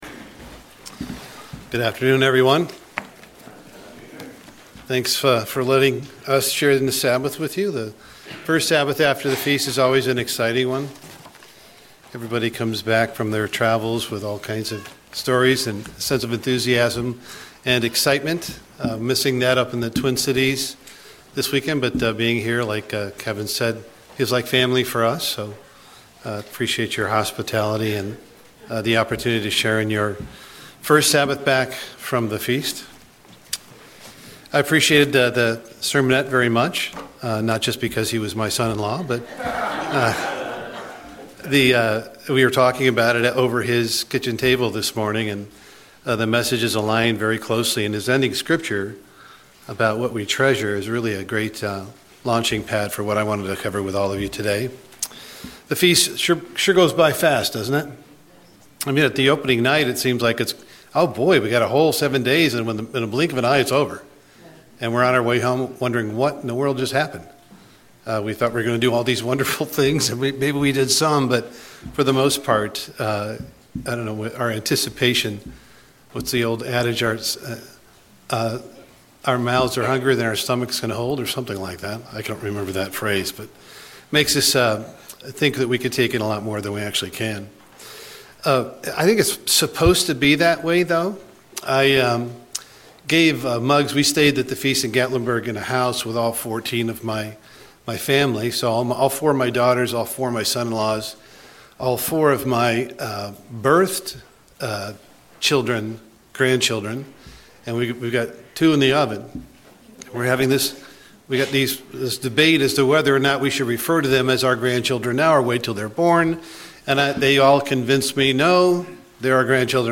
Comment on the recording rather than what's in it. Given in Portsmouth, OH Paintsville, KY